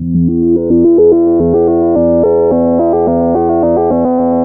JUP 8 E3 11.wav